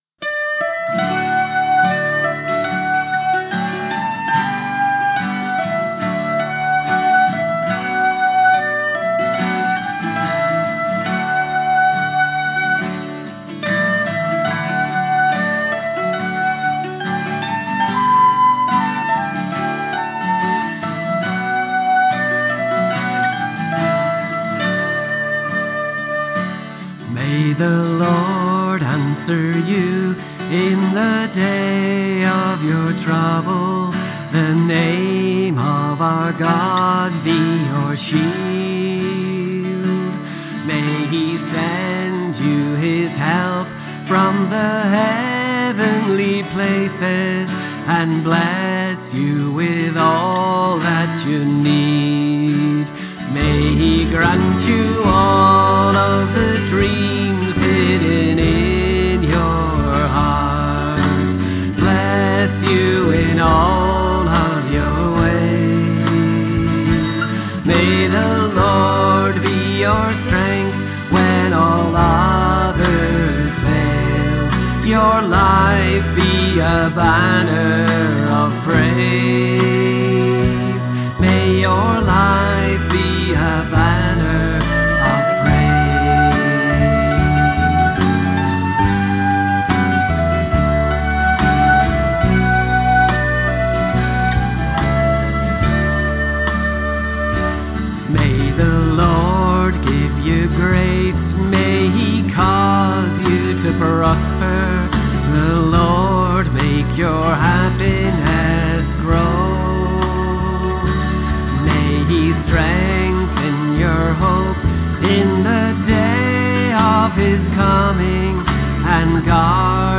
• Celtic